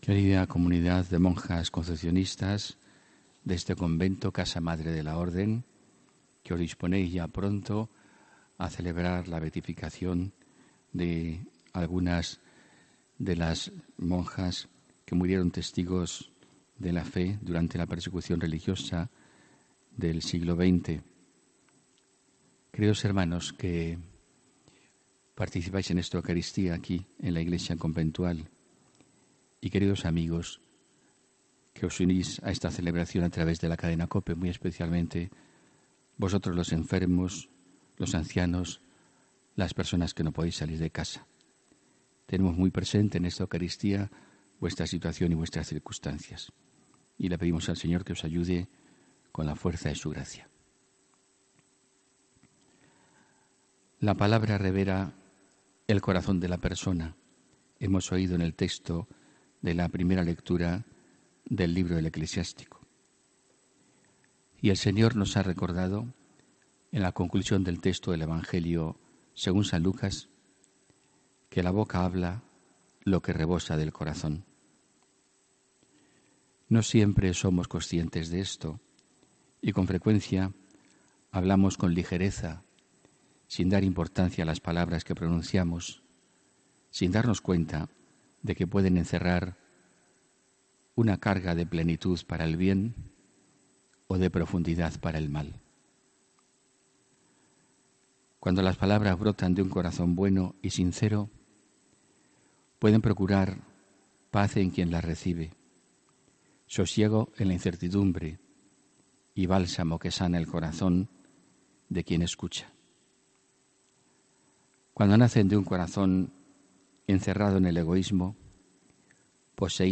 HOMILÍA 3 MARZO 2019